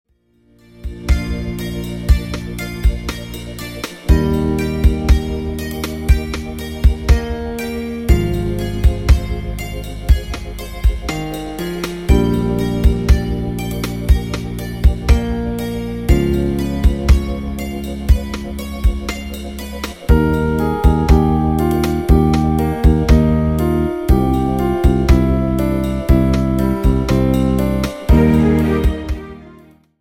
AMBIENT MUSIC  (4.34)